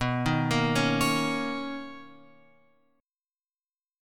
B Major 9th